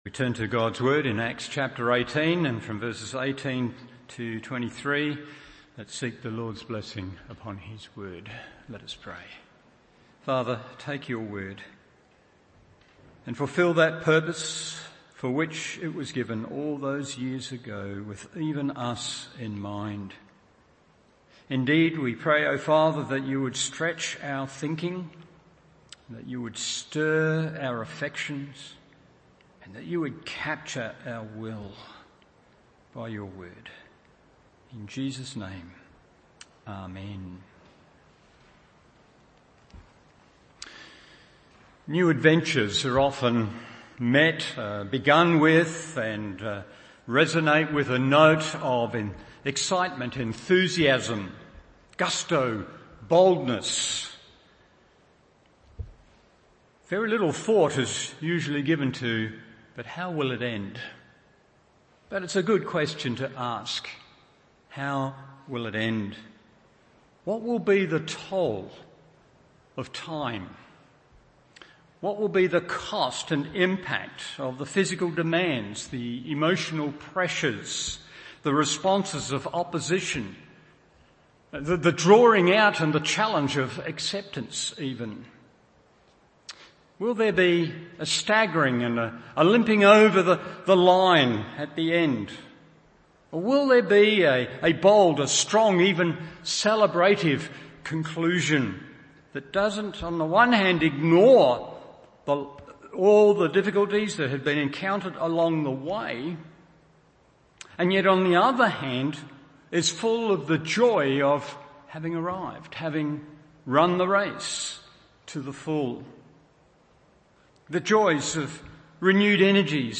Evening Service Acts 18:18-23 1. Looking Back with Gratitude 2. Move Forward with Commitment 3. Look around with Compassion…